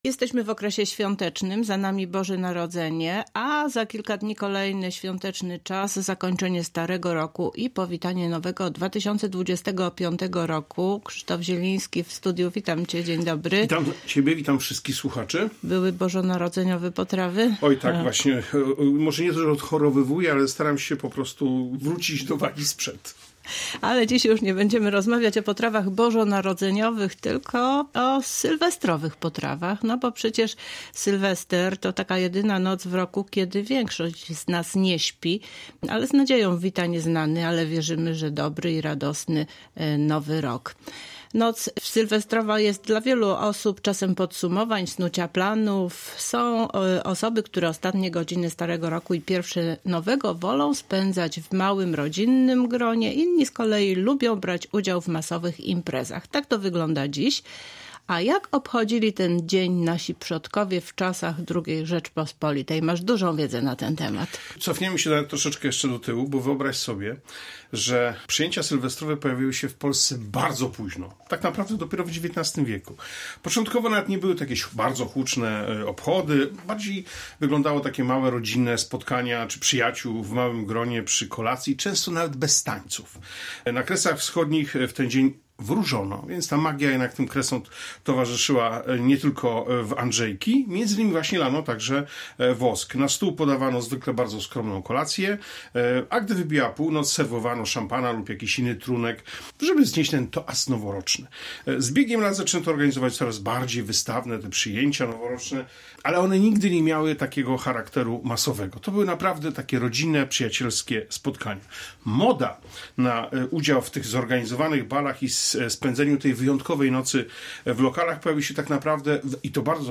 O historii sylwestrowej kuchni na przestrzeni lat rozmawiają w Kulinarnych Pogaduchach